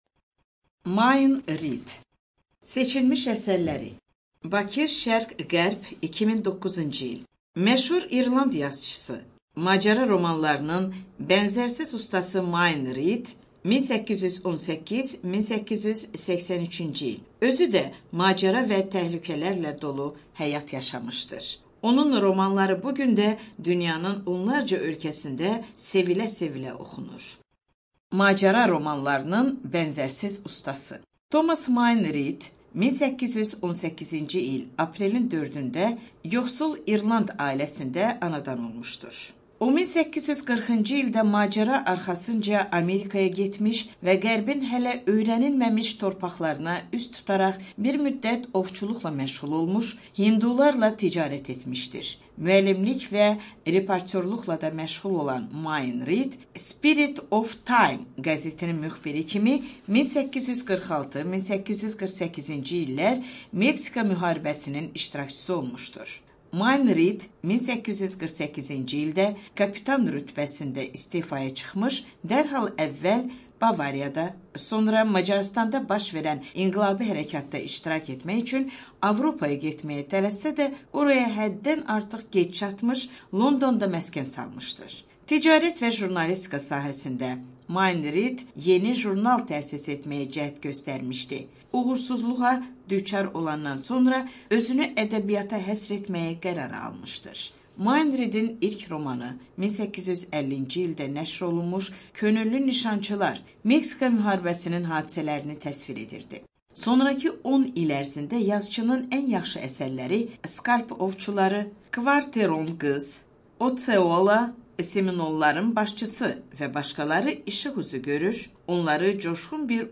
Студия звукозаписиСтудия Азербайджанского общества слепых